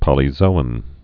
(pŏlē-zōən)